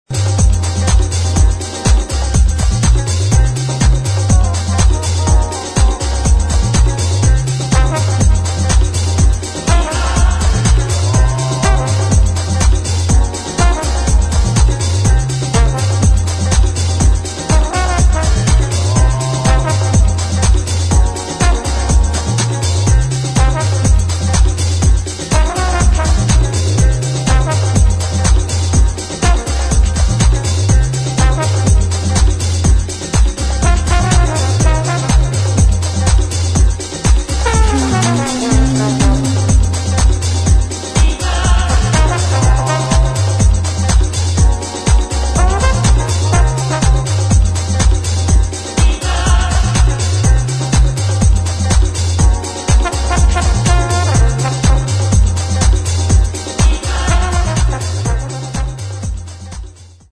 [ JAZZ | CROSSOVER ]
アフロ・テイストをテーマにしたクロスオーバー・シングル！！